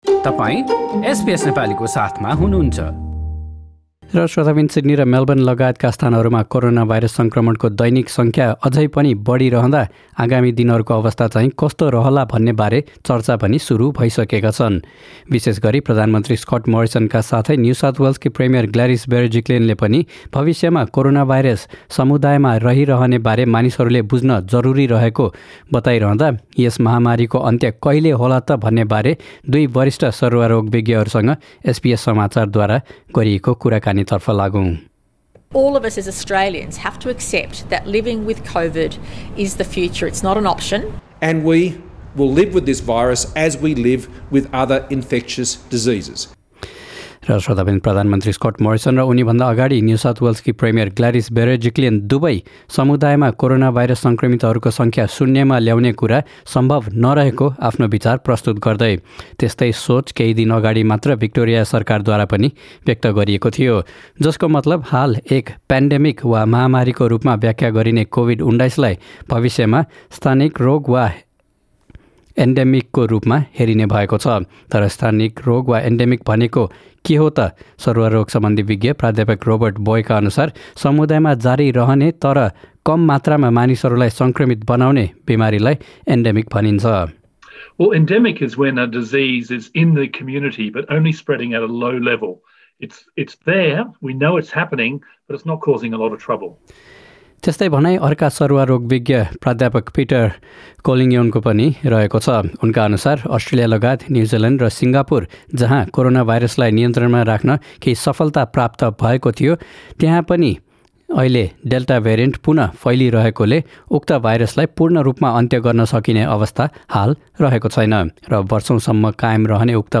With Australia's vaccination rate still rising, the conversation is moving towards what's ahead. SBS World News spoke to two of the country's leading epidemiologists to find out how, and when, the pandemic might end.